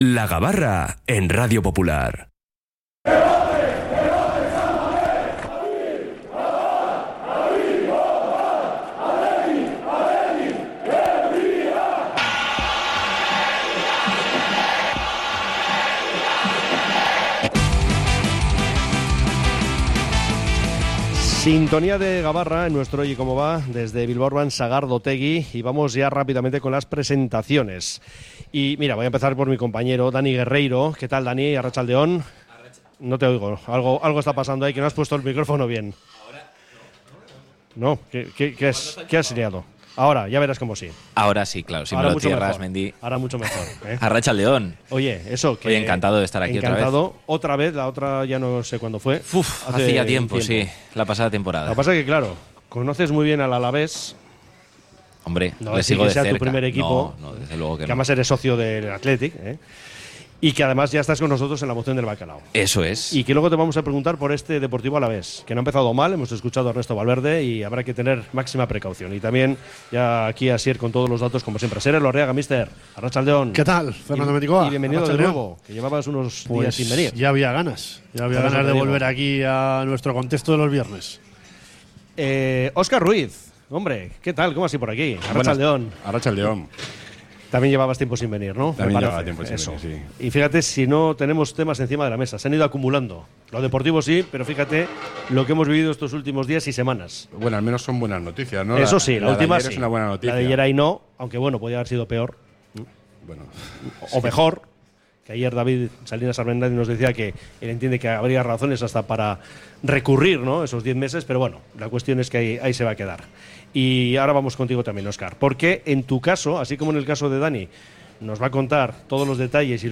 Desde Bilbao Urban Sagardotegi hemos repasado los muchos aspectos que ofrece la actualidad del Athletic